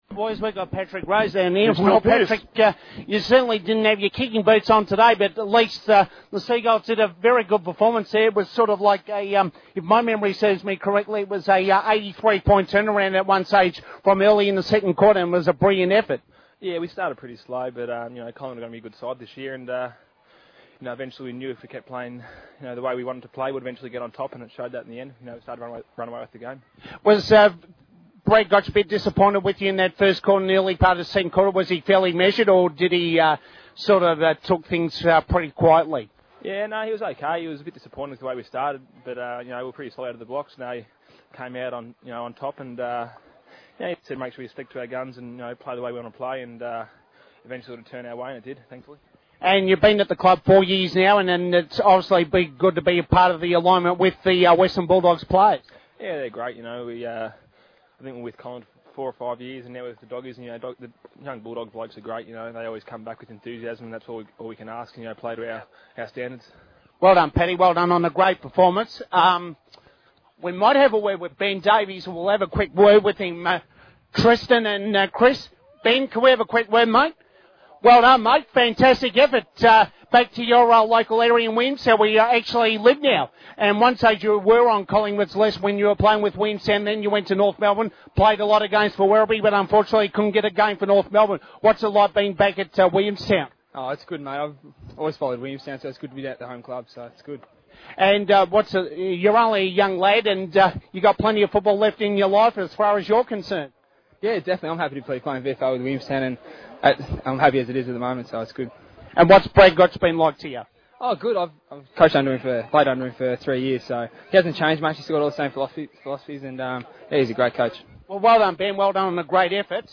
Casey FM's Round Two coverage of Collingwood versus Williamstown at Visy Partk, Carlton. First two parts include pregame show.